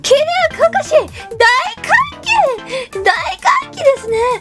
Worms speechbanks
Perfect.wav